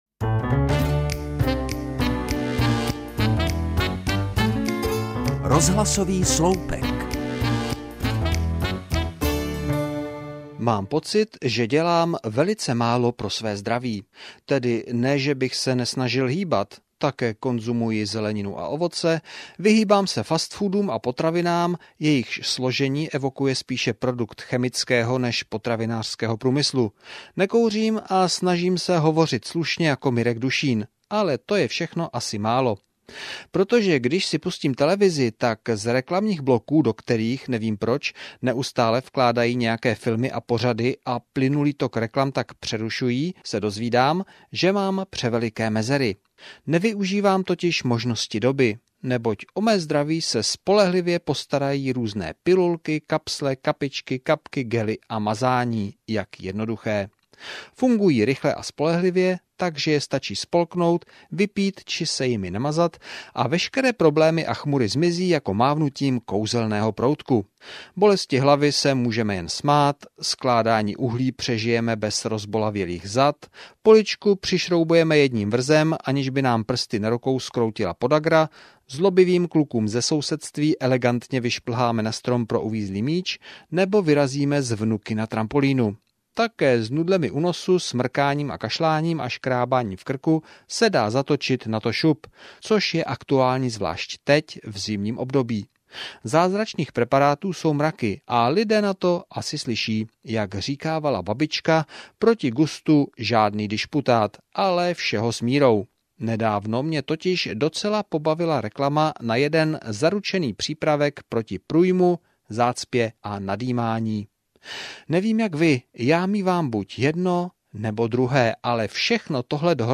Reportáže z jižních Čech, písničky na přání a dechovka.